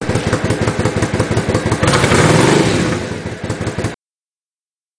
Second clip: three half inch holes in the second baffle plate.
But awesome volume, but not obnoxious, great tone...you decide.